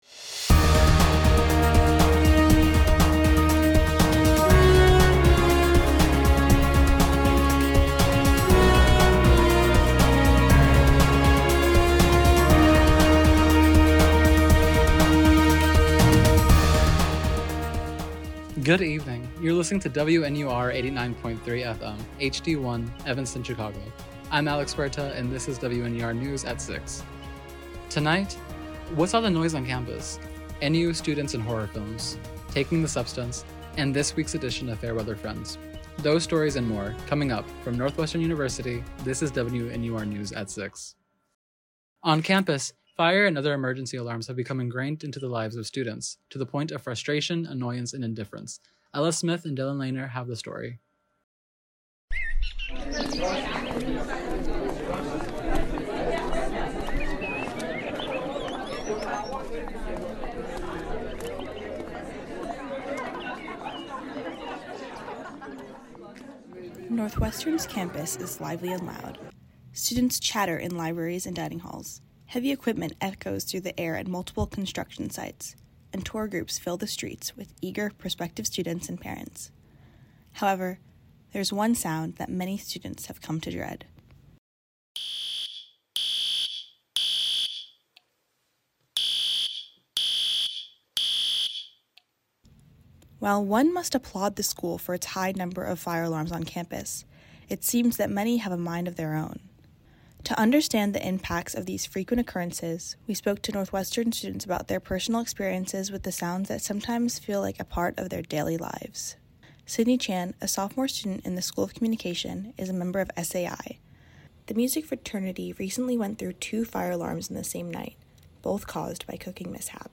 October 25, 2024: Campus fire alarms, Halloween, The Substance. WNUR News broadcasts live at 6 pm CST on Mondays, Wednesdays, and Fridays on WNUR 89.3 FM.